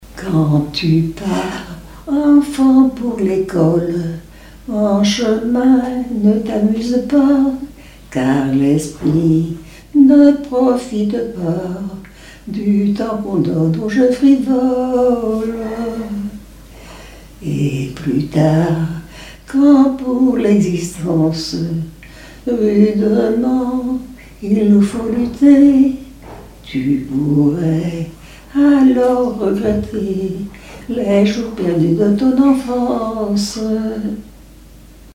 circonstance : éducation scolaire
Genre brève
Pièce musicale inédite